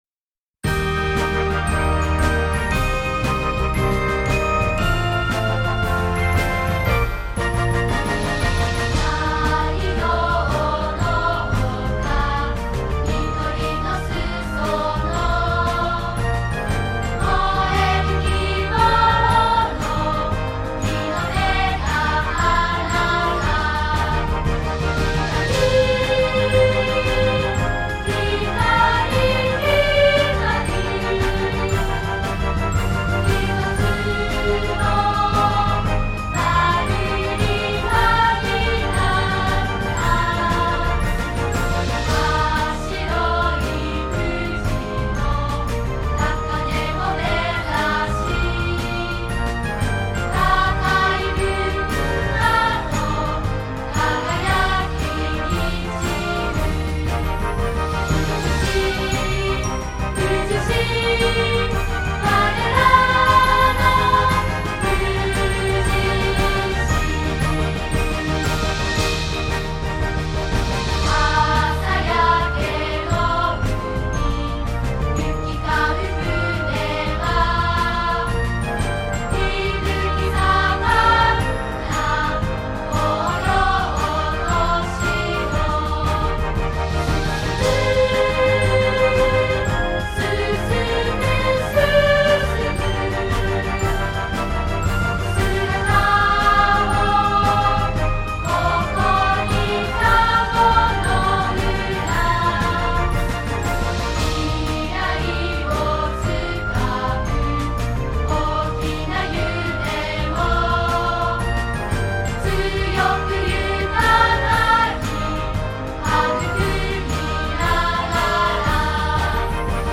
斉唱を聞く（3分23秒）（MP3：3,183KB） 伴奏のみを聞く（3分23秒）（MP3：3,139KB） 楽譜について 富士市民歌の楽譜をシティプロモーション課で配布しています。